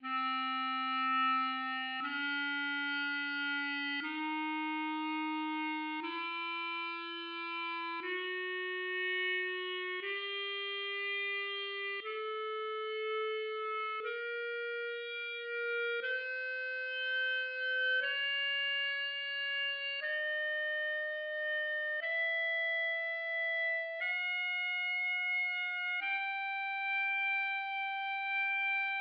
This provides a very close match to justly tuned ratios consisting only of odd numbers. Each step is 146.3 cents (
BP_scale_et.mid.mp3